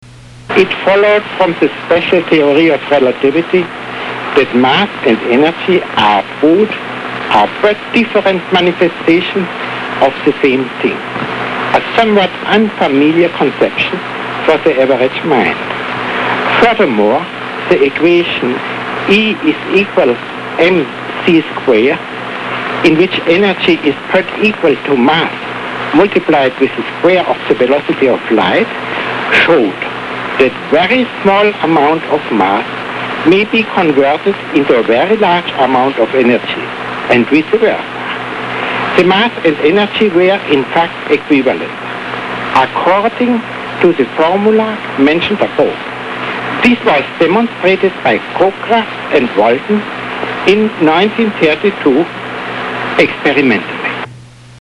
e = mc2 (voz original, 853 kbs, formato mp3)
Einstein explica la equivalencia entre energía y materia